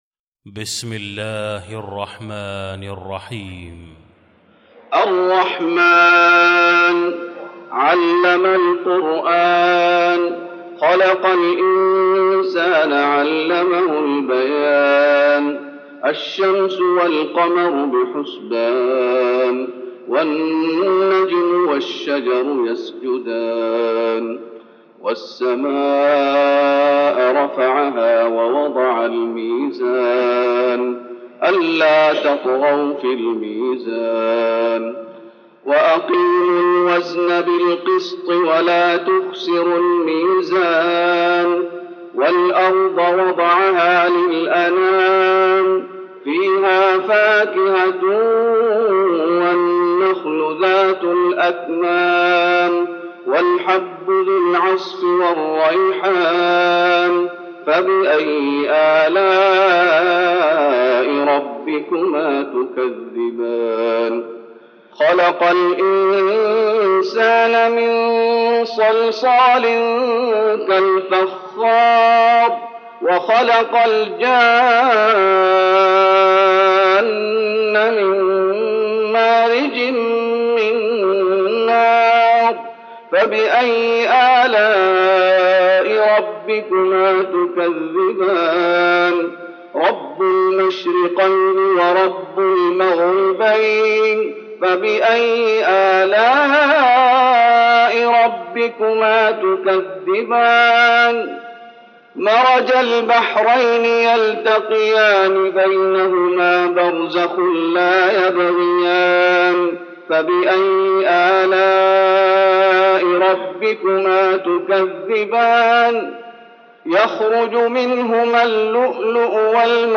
المكان: المسجد النبوي الرحمن